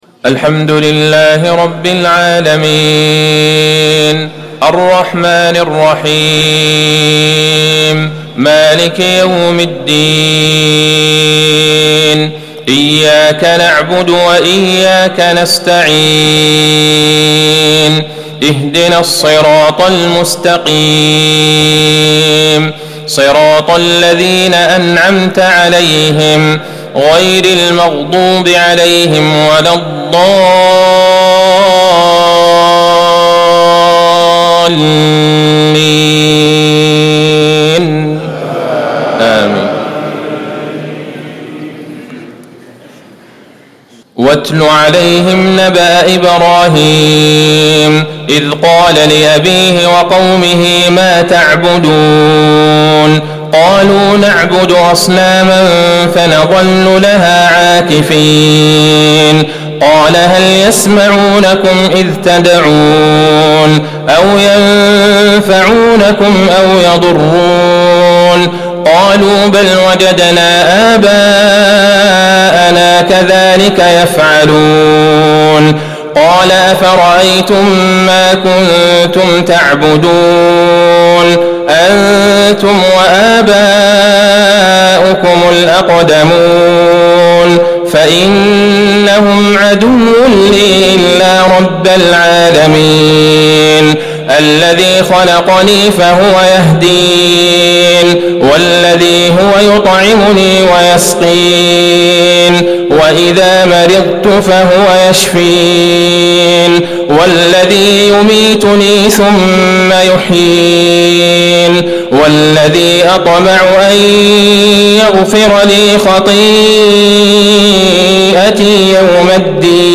صلاة العشاء 3-5-1440هـ من سورة الشعراء | Isha prayer 9-1-2019 from surah Ash-shura > 1440 🕌 > الفروض - تلاوات الحرمين